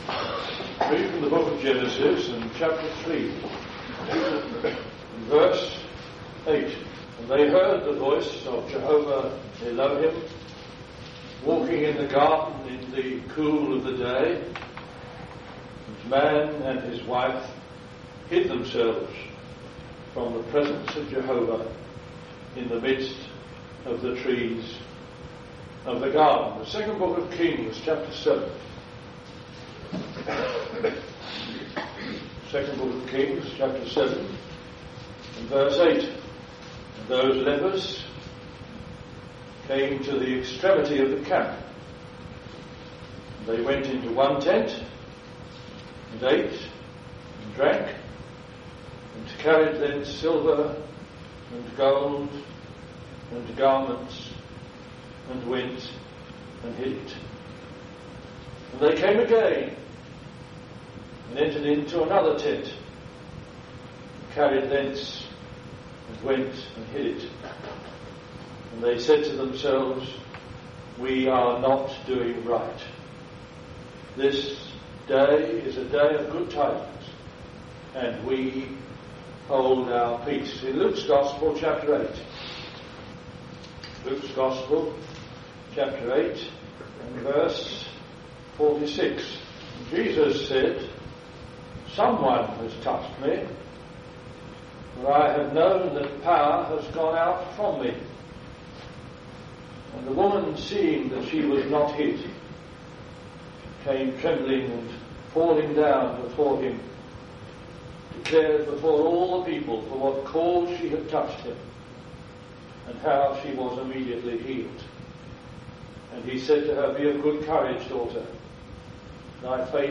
In this Gospel preaching, you will hear what God has done to bring us into eternal life through His son the Lord Jesus Christ.